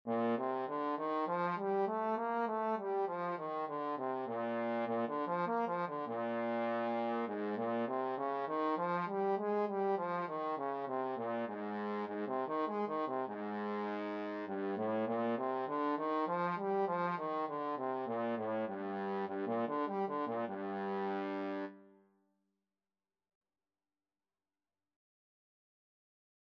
Trombone scales and arpeggios - Grade 1
Bb major (Sounding Pitch) (View more Bb major Music for Trombone )
G3-Bb4
4/4 (View more 4/4 Music)
trombone_scales_grade1.mp3